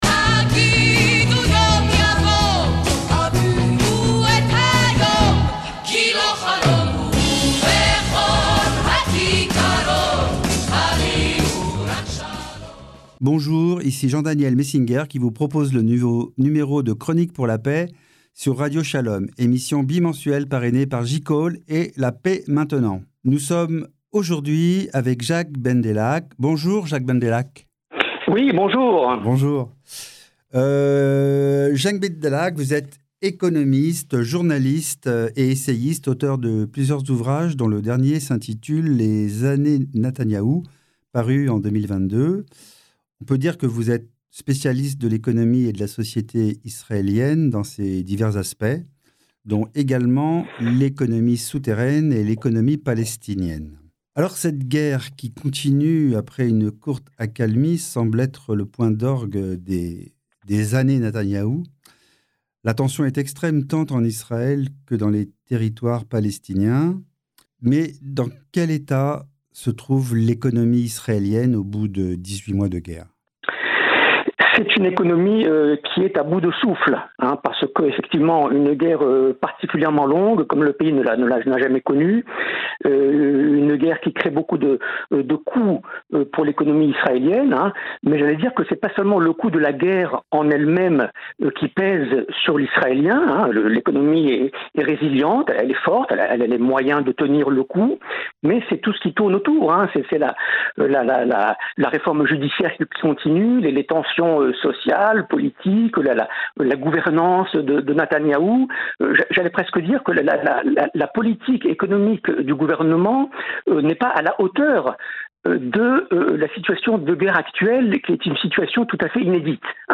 Dans le cadre de Chroniques pour la Paix, émission bimensuelle de Radio Shalom parrainée par La Paix Maintenant et JCall